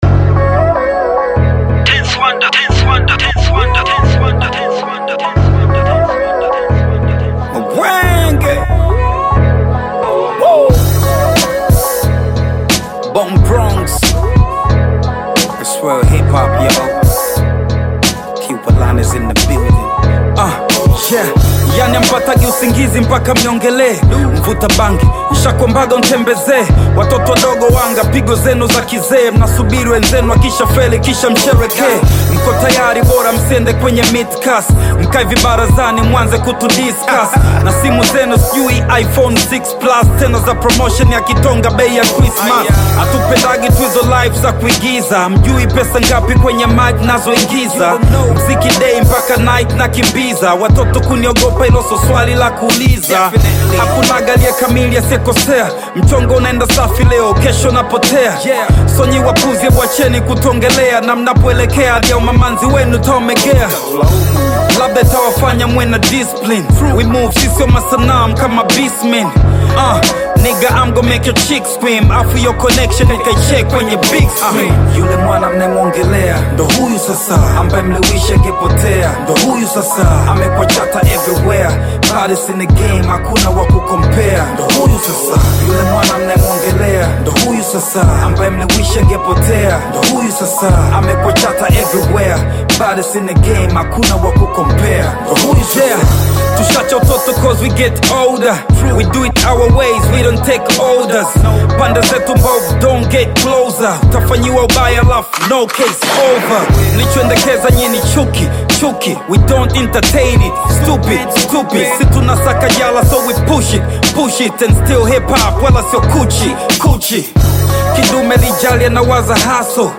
Bongo Flava music track
Bongo Flava song
This catchy new song